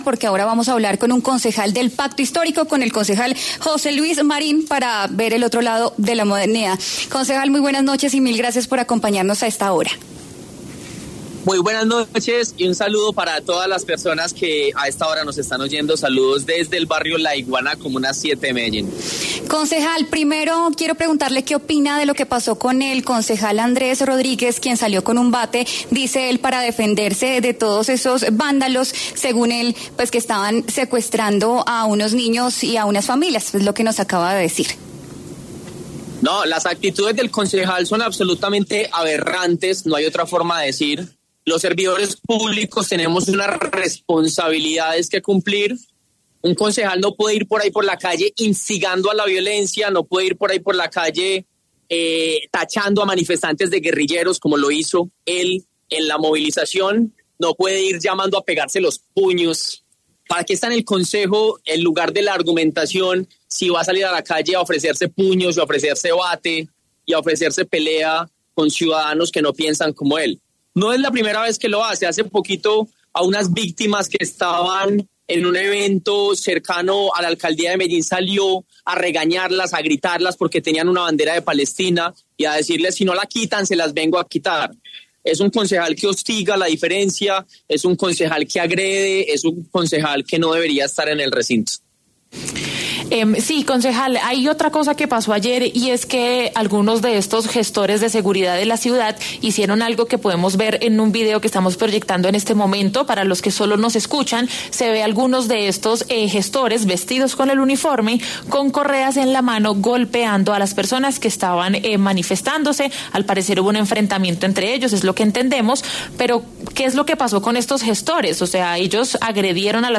Es por eso por lo que el concejal José Luis Marín, del Pacto Histórico, pasó por los micrófonos de W Sin Carreta y dio su opinión de lo sucedido.